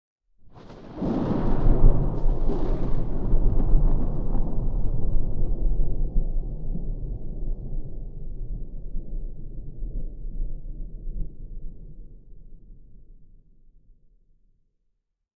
thunderfar_25.ogg